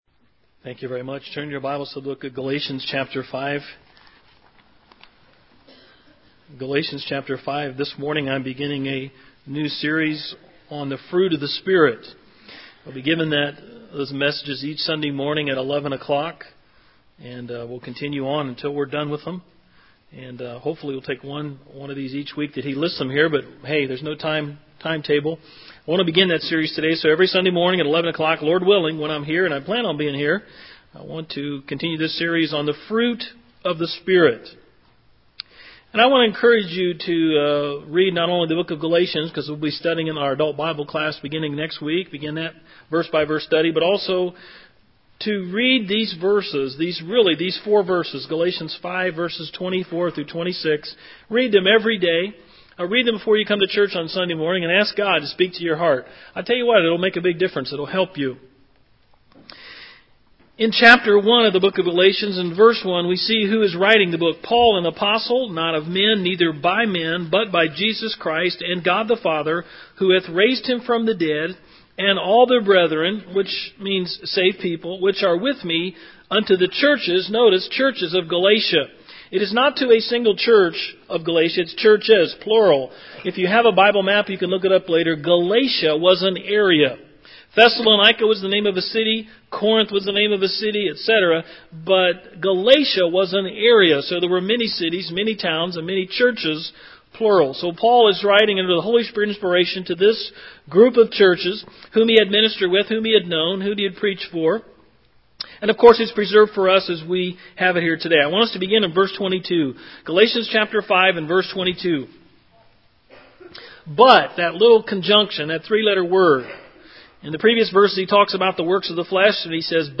Sermons - Anchor Baptist Church